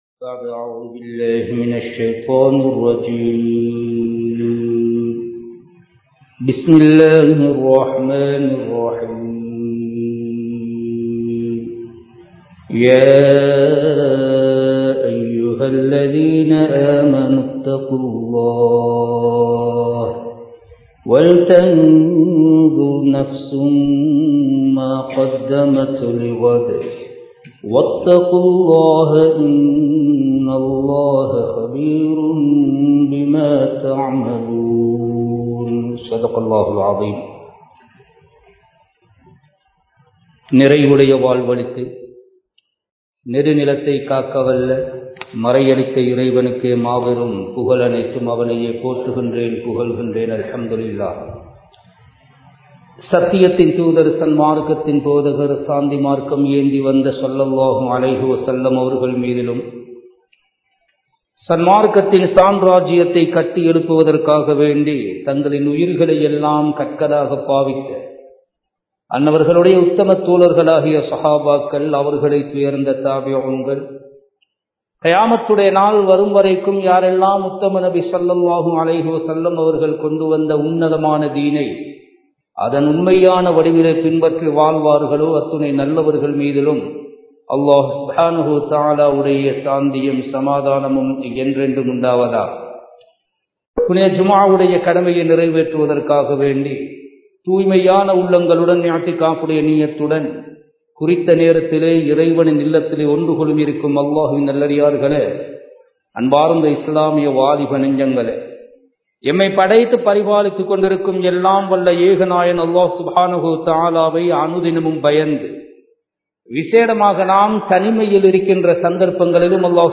உமர் (ரழி) அவர்களின் ஆட்சி முறை | Audio Bayans | All Ceylon Muslim Youth Community | Addalaichenai
Kandy, Kattukela Jumua Masjith